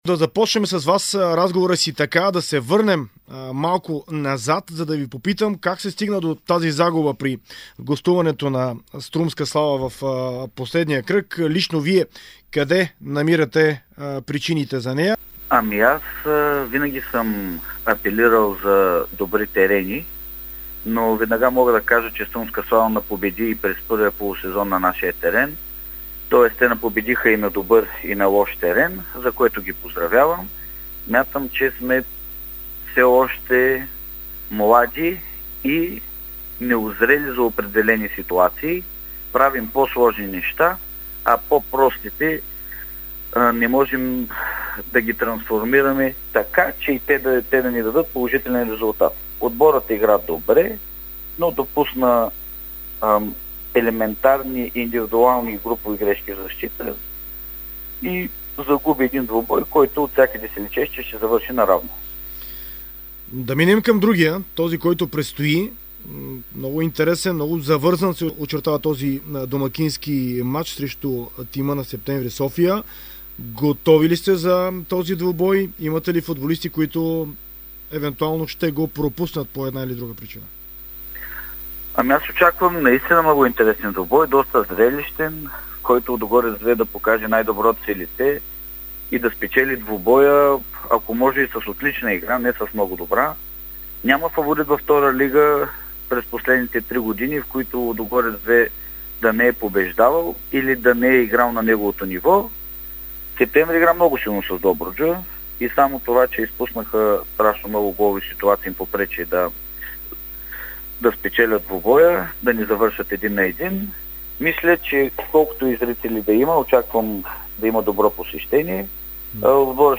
интервю за Дарик радио и dsport своите очаквания за този двубой. Според него мачът ще е изключително интересен и зрелищен, като се надява Лудогорец II да го спечели с отлична игра.